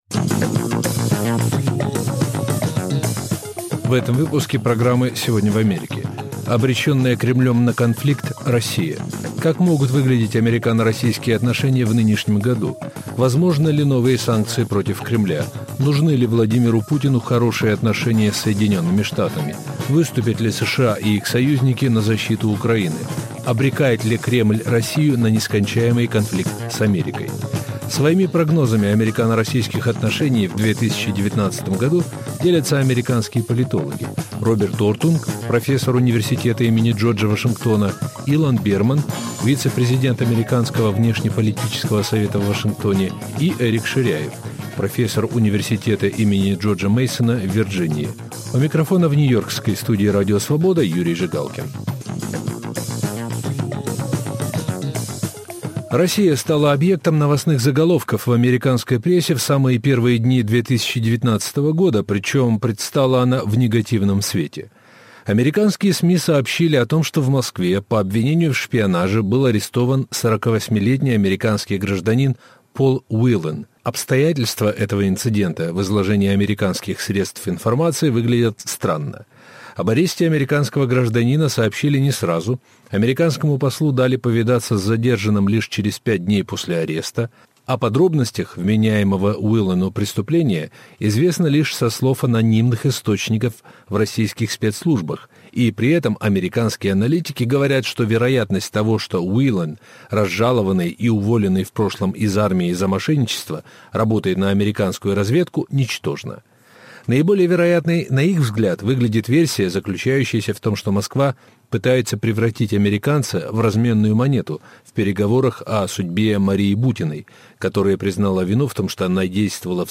Как могут выглядеть американо-российские отношения в нынешнем году? Возможны ли новые санкции против Кремля? Своими прогнозами американо-российских отношений в 2019 году делятся американские политологи